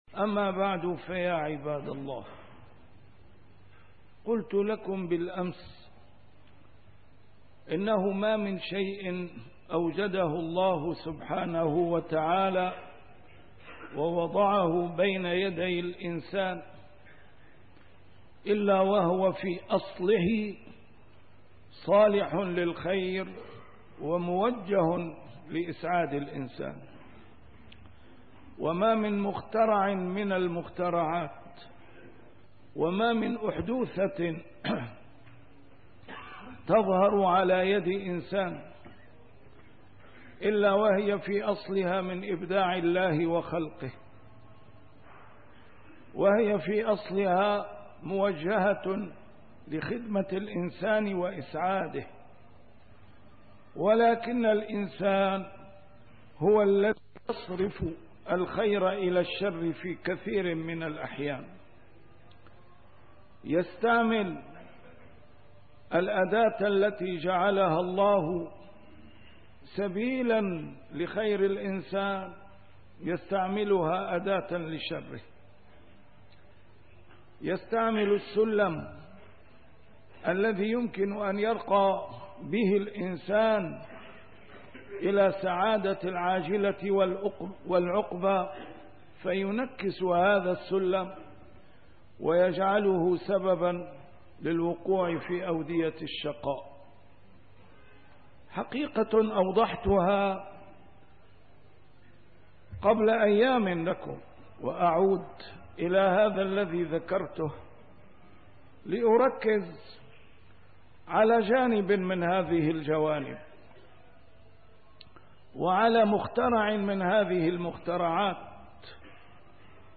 A MARTYR SCHOLAR: IMAM MUHAMMAD SAEED RAMADAN AL-BOUTI - الخطب - أثر الأطباق المتكاثرة على أسطح المنازل